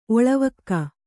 ♪ oḷavakka